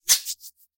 чуть заметный